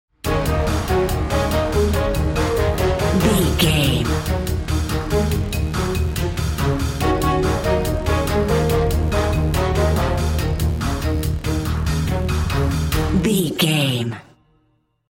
Aeolian/Minor
E♭
epic
intense
medium tempo
bass guitar
drum machine
brass
percussion
strings